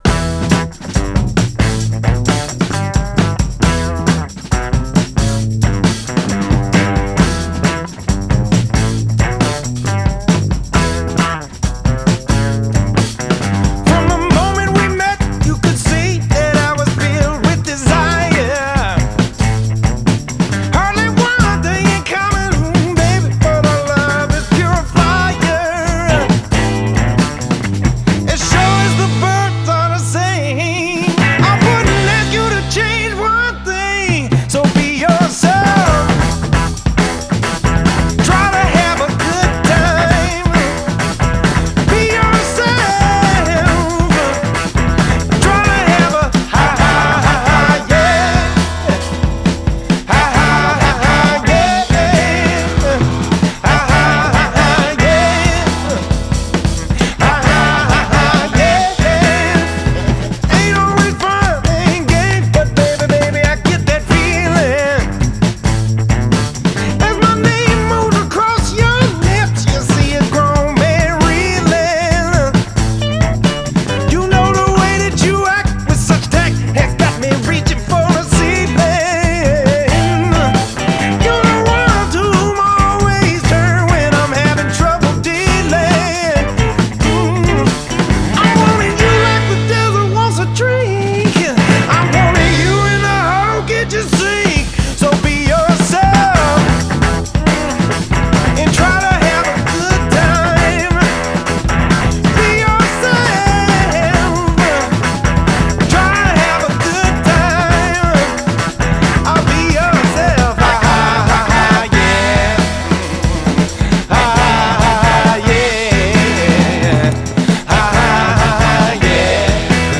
can be both proggy and punky